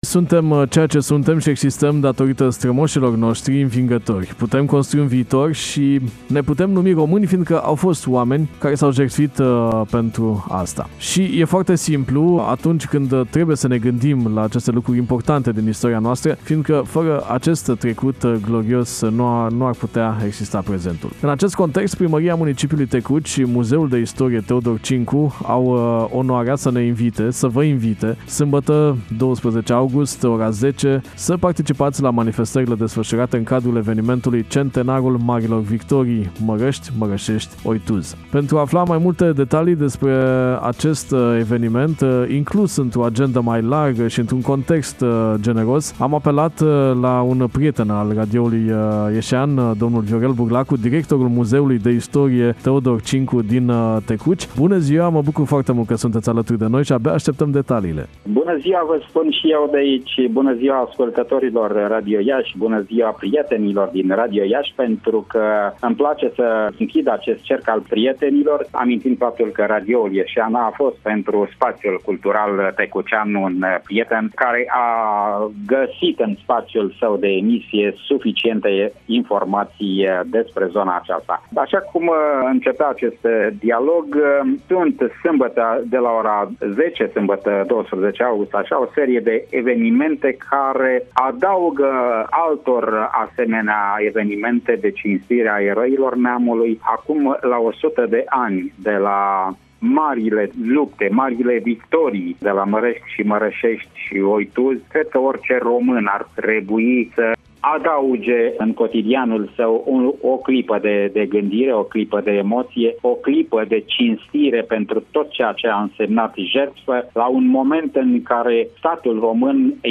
(INTERVIU) Evenimente la Tecuci cu prilejul Centenarului marilor victorii de la Mărăşeşti, Mărăşti şi Oituz”